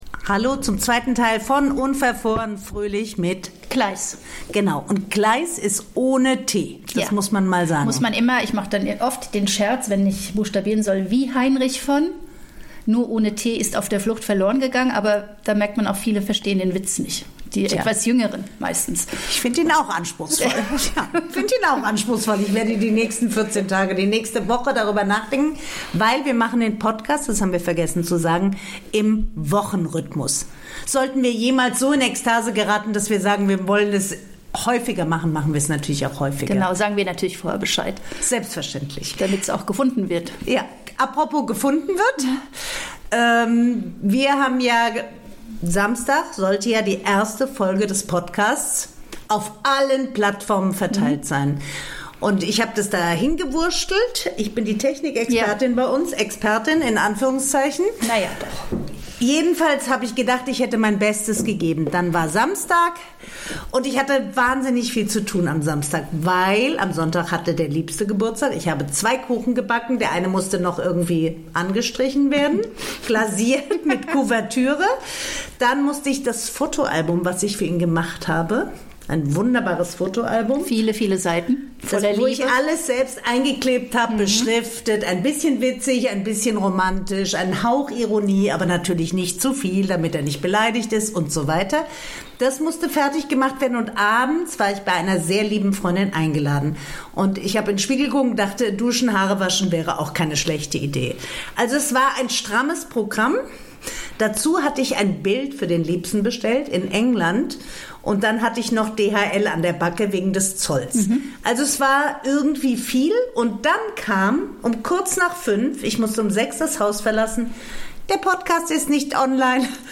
reden die beiden Podcasterinnen